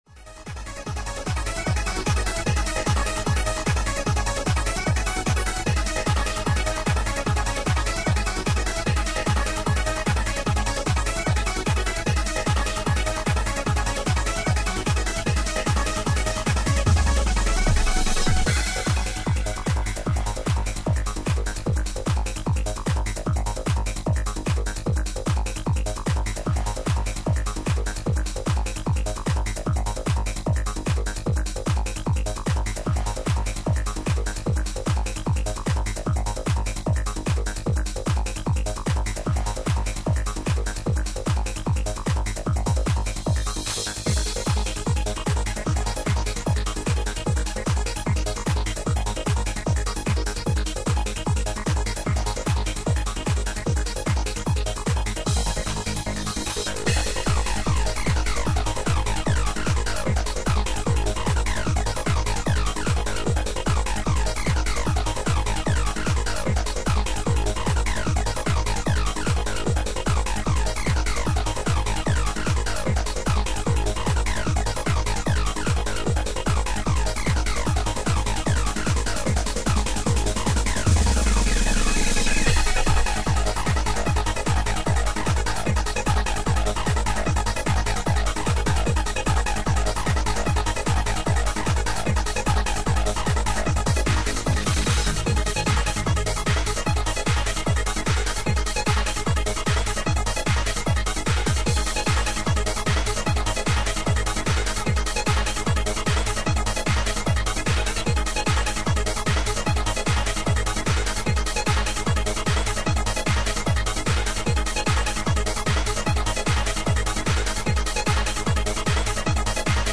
Live Set